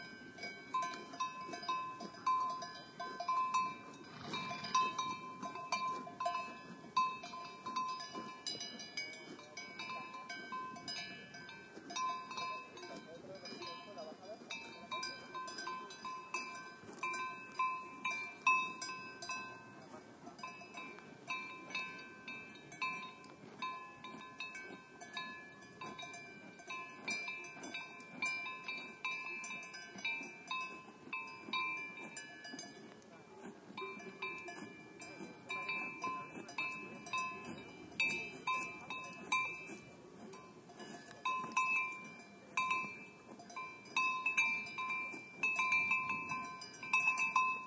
描述：马铃声，有一些遥远的声音。PCM M10录音机，内置话筒。在法国比利牛斯山脉Midi d'Ossau masif旁的Refuge de Pombie附近录制
标签： 氛围 钟声 现场录音 比利牛斯
声道立体声